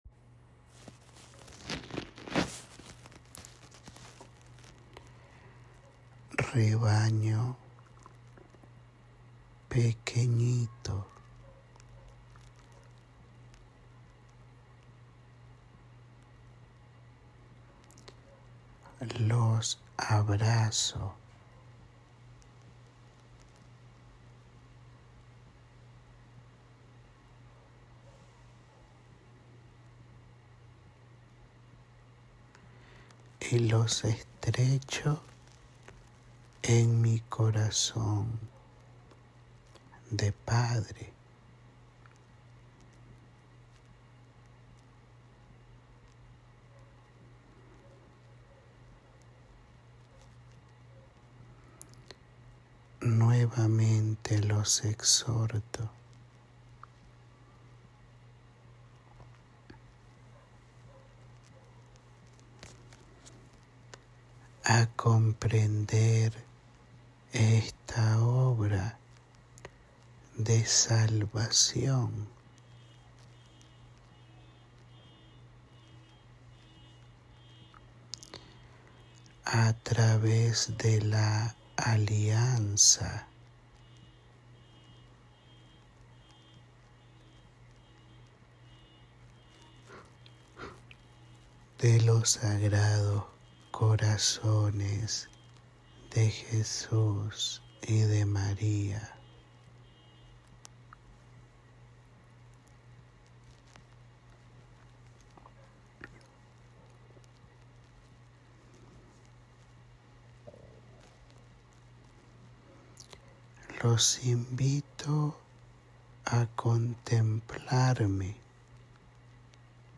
Áudio da Mensagem